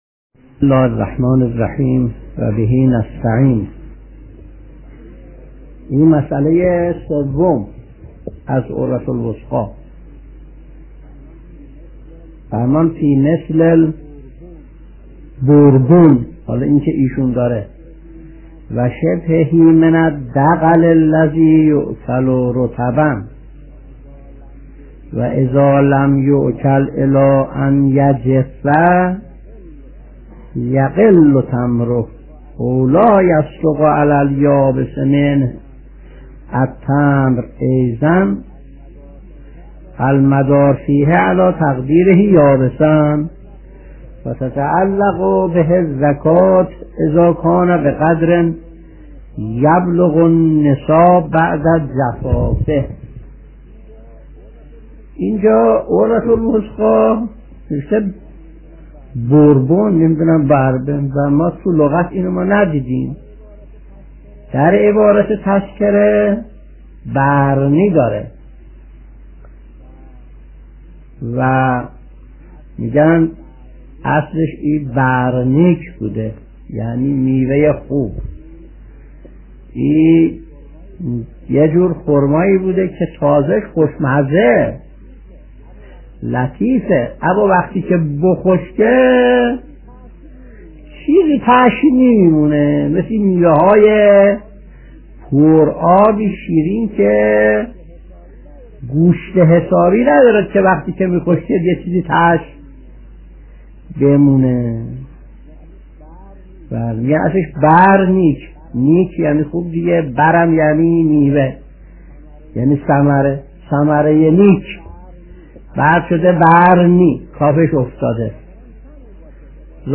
درس 191 : (10/3/1362)